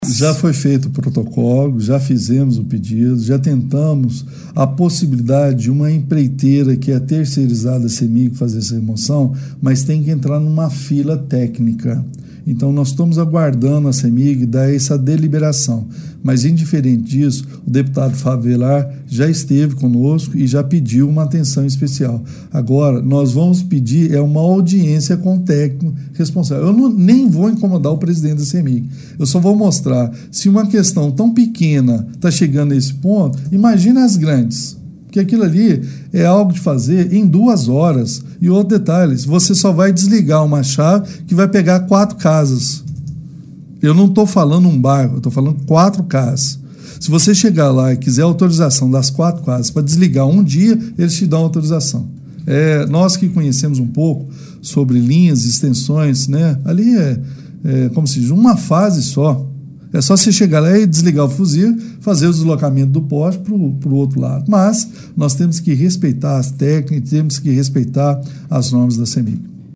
Ele responde que está ciente do problema e tentando outras alternativas para mudar o poste de lugar, até mesmo com uma empresa terceirizada, já que a concessionária não fez a remoção, impedindo a conclusão da quadra poliesportiva da nova escola de Meireles: